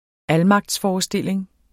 Udtale [ ˈalmɑgds- ]